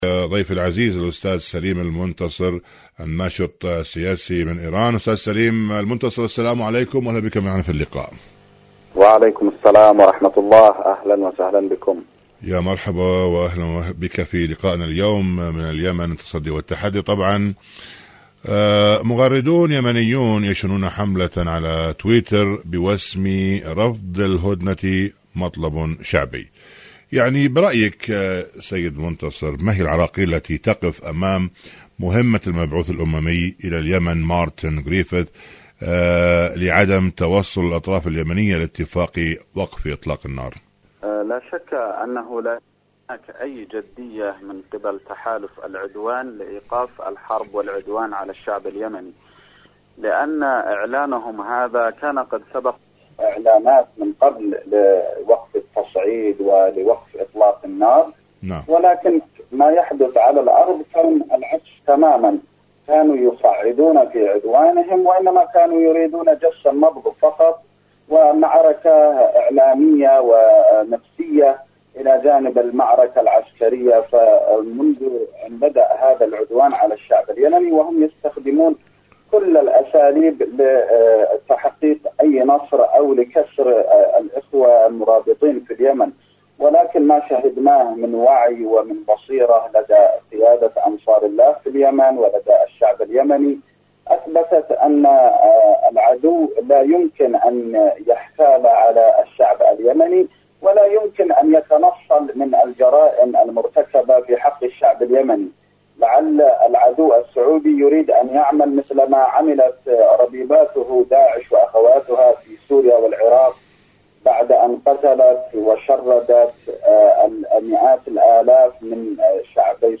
مقابلة
إذاعة طهران-اليمن التصدي والتحدي: مقابلة إذاعية